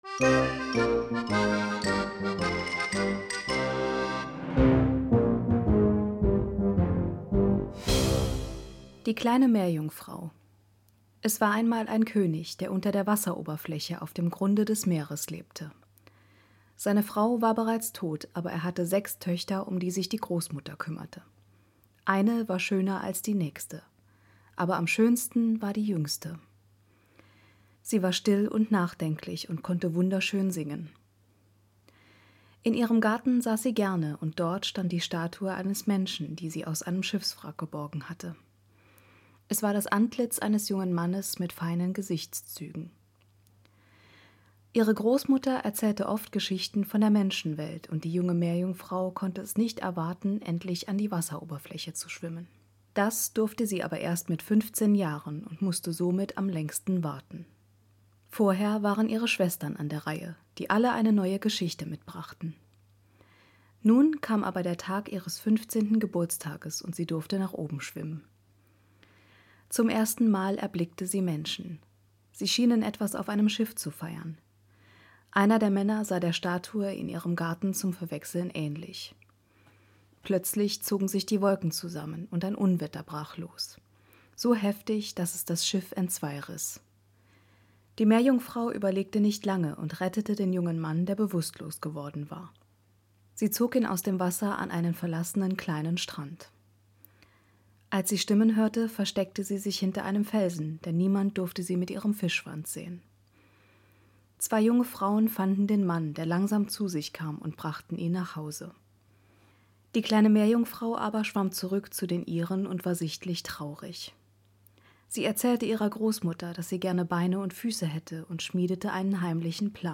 Dieses Märchen wurde von mir nacherzählt.